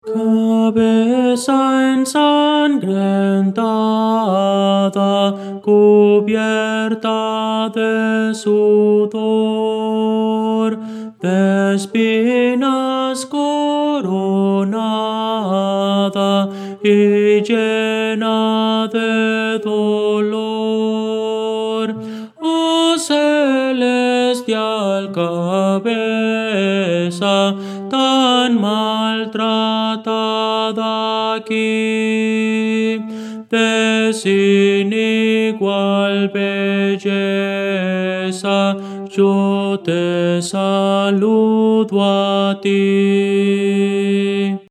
Voces para coro
Tenor
Audio: MIDI